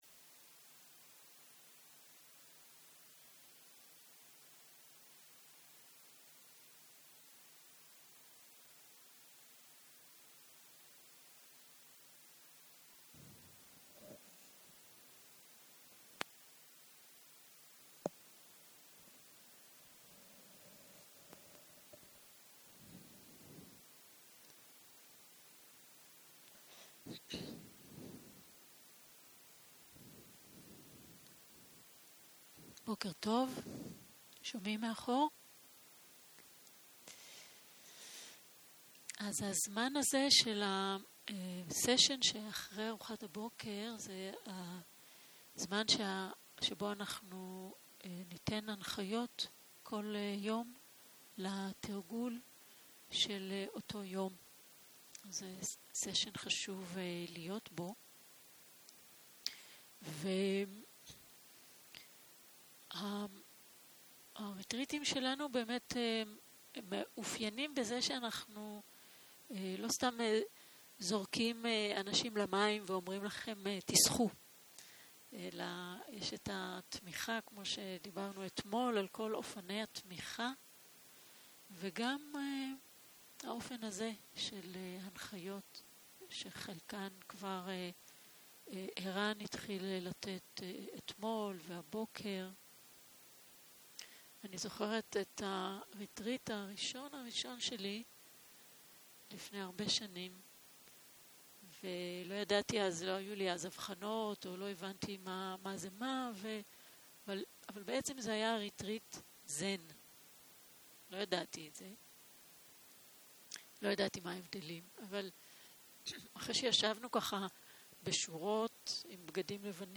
30.03.2023 - יום 2 - בוקר - הנחיות מדיטציה - הקלטה 2
Dharma type: Guided meditation שפת ההקלטה